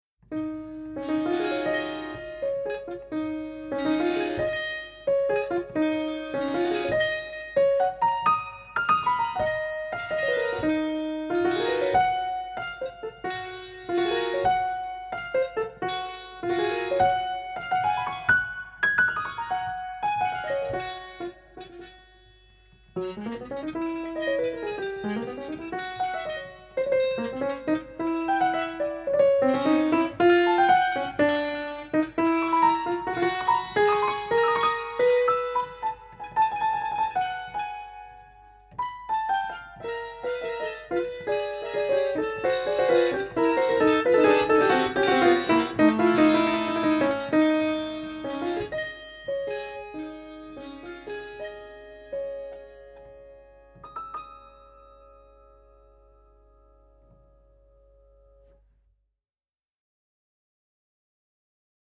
per pianoforte